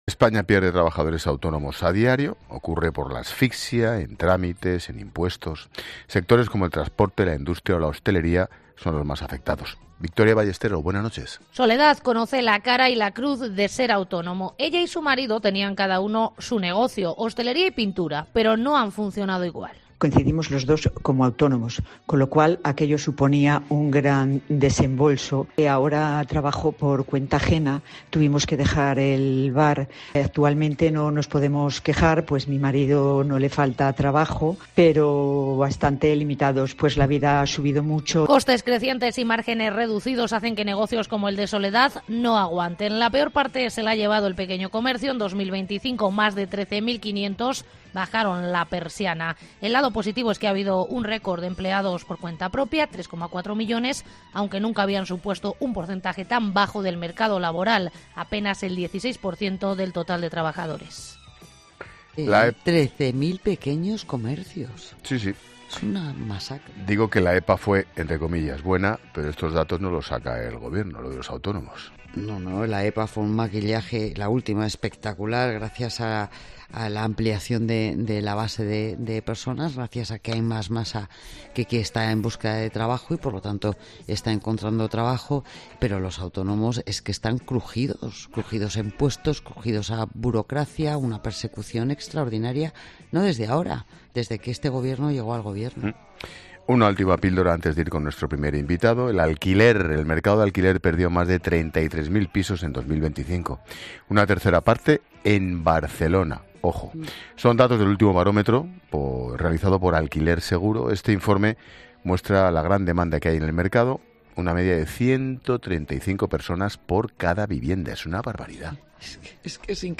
"Coincidimos los 2 como autónomos, con lo cual aquello suponía un gran desembolso", ha relatado en antena.